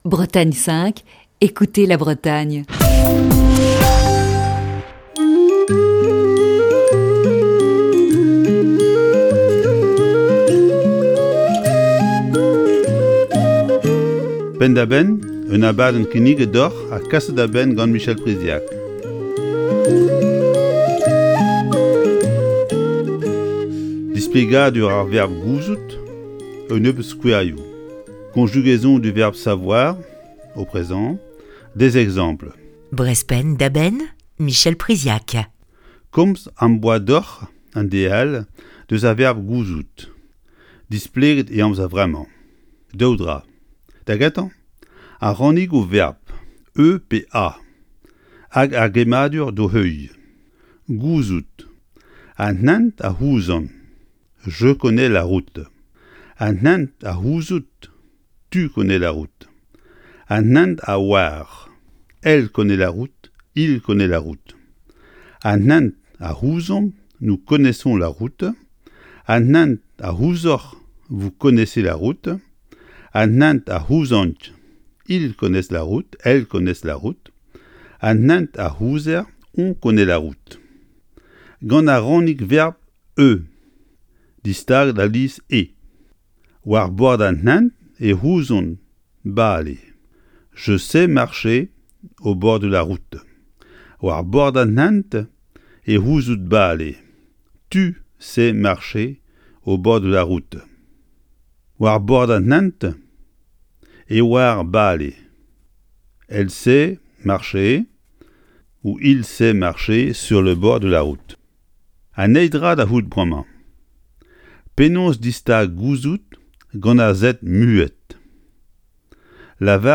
Fil d'Ariane Accueil Les podcasts Le verbe "gouzout" (Communiquer, savoir) Le verbe "gouzout" (Communiquer, savoir) Chronique du 25 mars 2021.